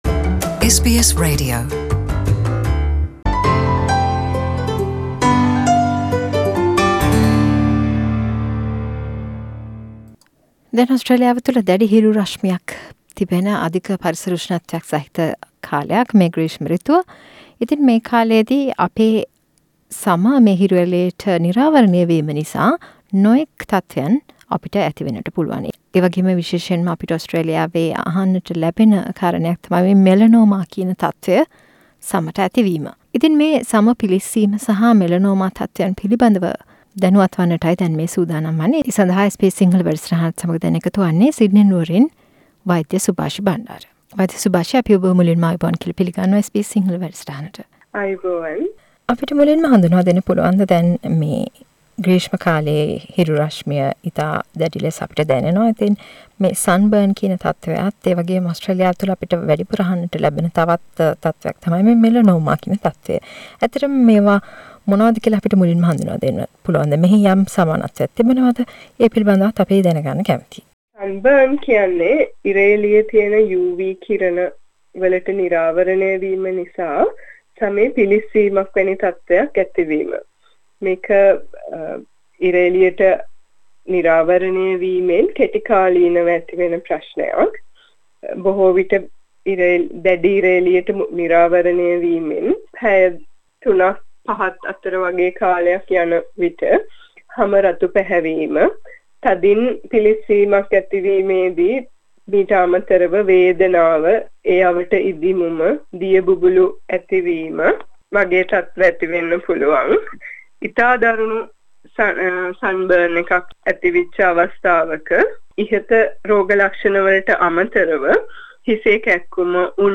SBS සිංහල සිදු කල සාකච්චාවක්